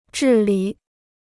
治理 (zhì lǐ): to govern; to administer.